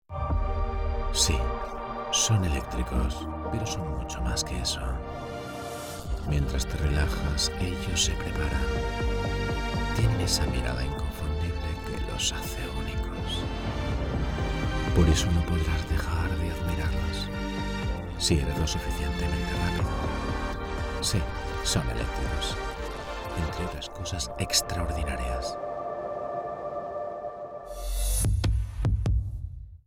Voz neutral, emotiva, energética y divertida
Profesional Studio at home